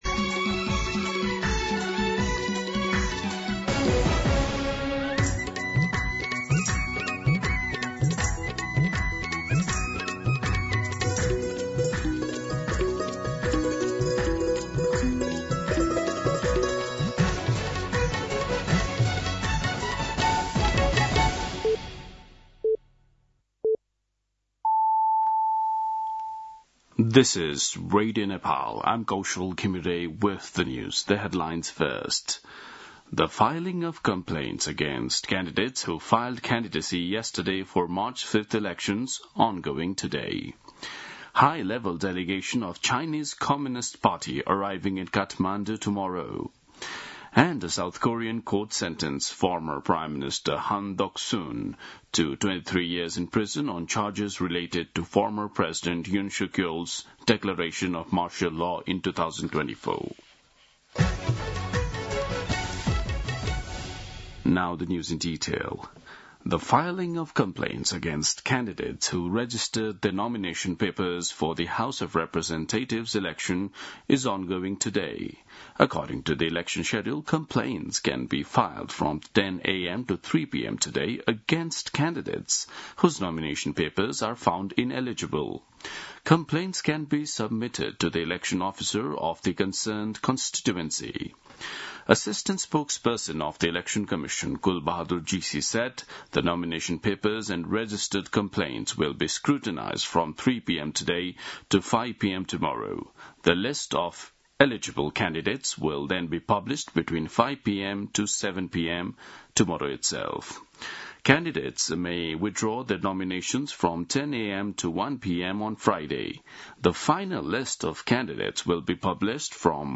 दिउँसो २ बजेको अङ्ग्रेजी समाचार : ७ माघ , २०८२
2pm-English-News-3.mp3